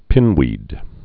(pĭnwēd)